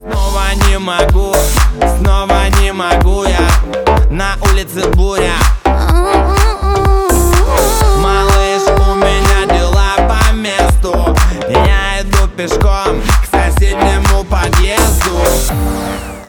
поп
громкие